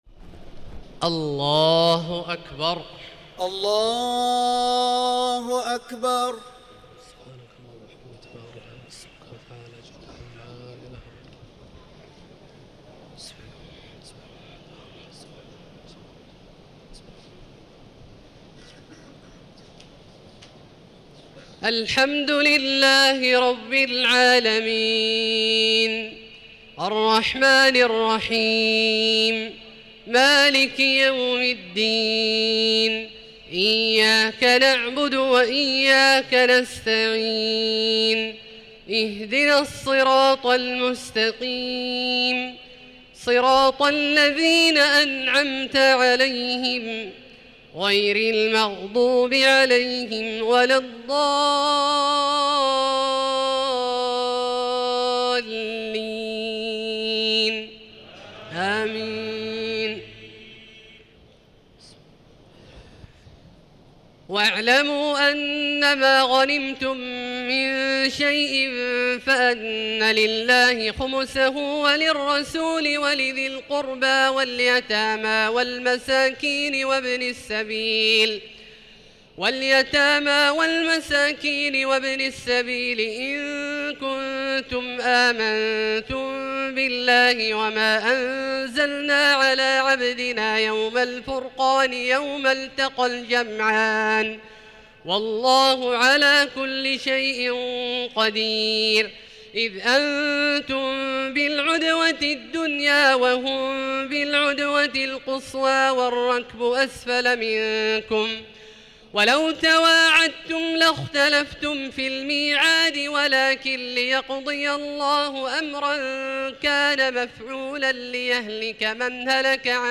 تهجد ليلة 30 رمضان 1437هـ من سورتي الأنفال (41-75) و التوبة (1-40) Tahajjud 30 st night Ramadan 1437H from Surah Al-Anfal and At-Tawba > تراويح الحرم المكي عام 1437 🕋 > التراويح - تلاوات الحرمين